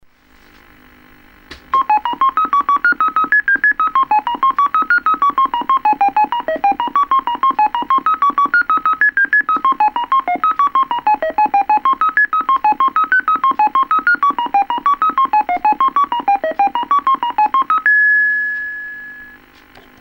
left the recorder in front of the TV so its not HD quality.
Crude recordings of my endeavours today